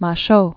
(mä-shō), Guillaume de 1300?-1377.